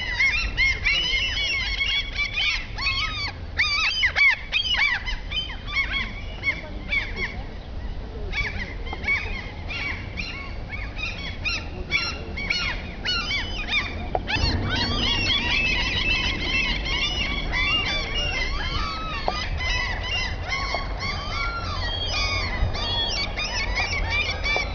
Abb. 12: zwei Silbermöven kümmern sich lautstark um die Verteidigung ihres Nachwuchses. (FB)
Abb. 13: Silbermöven, lineare Frequenzachse,